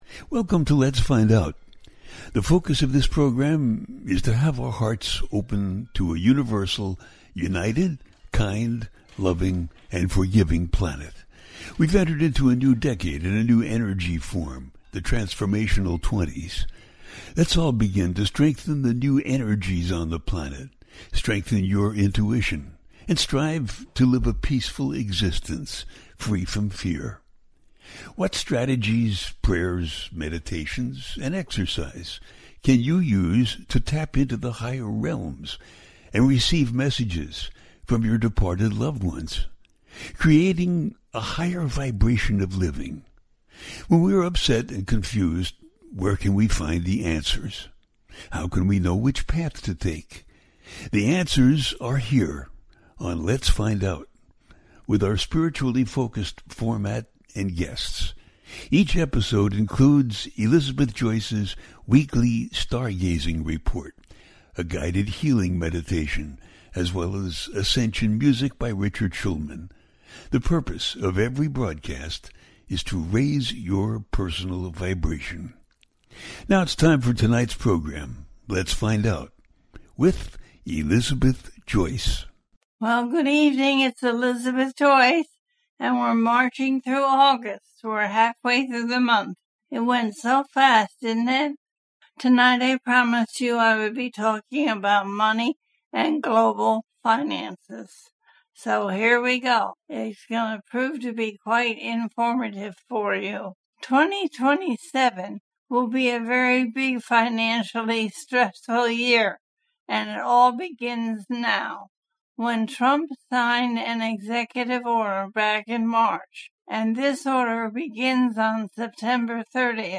Finances and Astrology - A teaching show
The listener can call in to ask a question on the air.
Each show ends with a guided meditation.